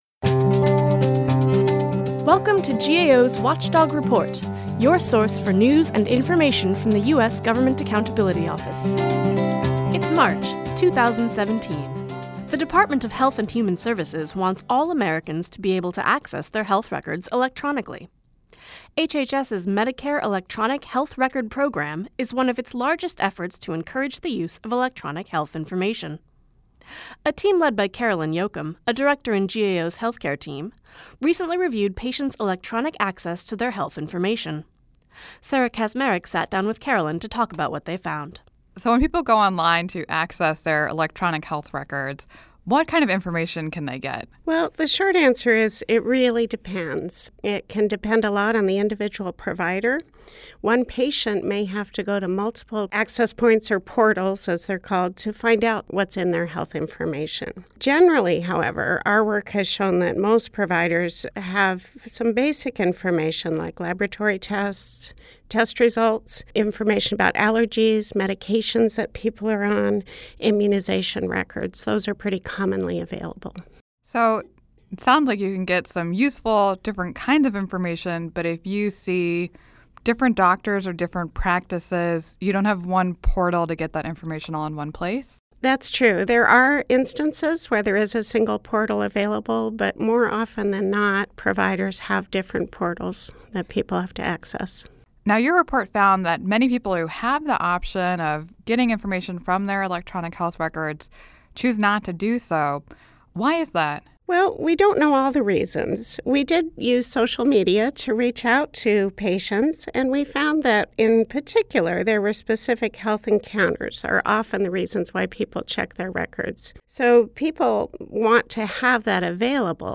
Director